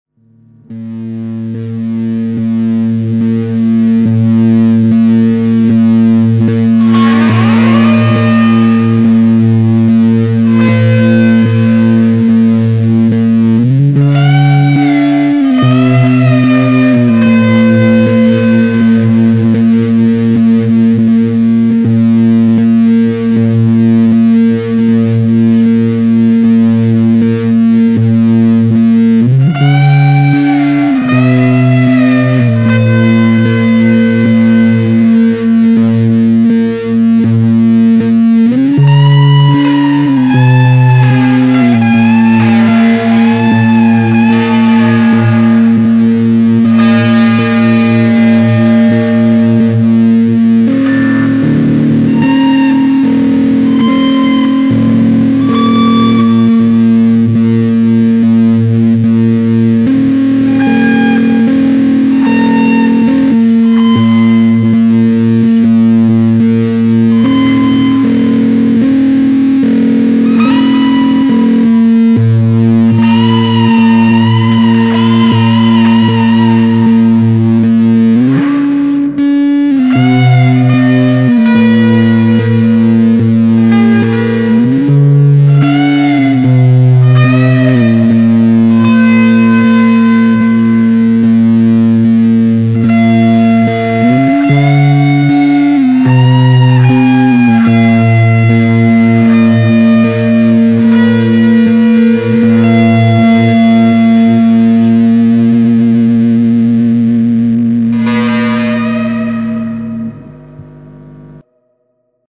Это инструменталь "пространство холода" - про космос:
cold.mp3